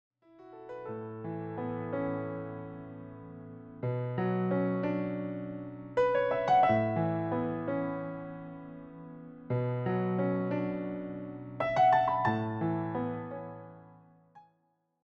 piano pieces
adds a bit more motion while still keeping things grounded.